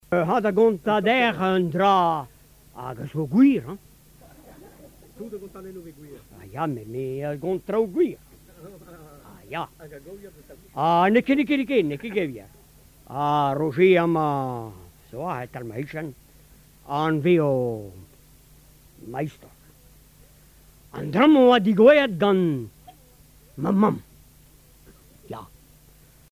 Genre conte
Catégorie Récit